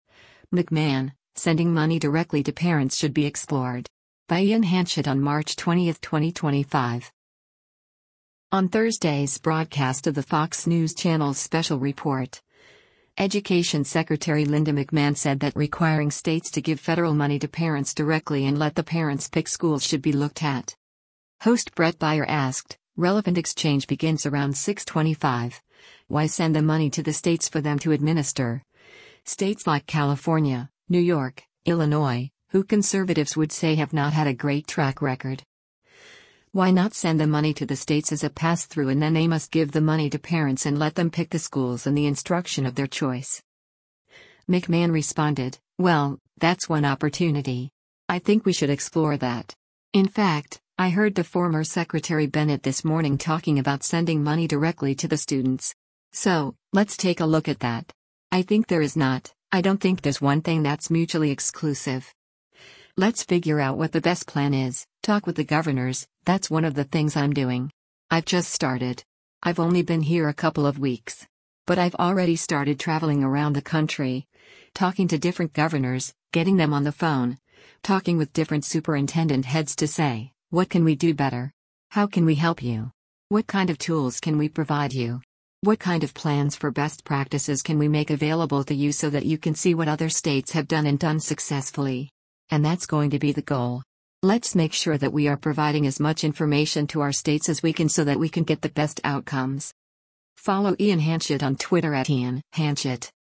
On Thursday’s broadcast of the Fox News Channel’s “Special Report,” Education Secretary Linda McMahon said that requiring states to give federal money to parents directly and let the parents pick schools should be looked at.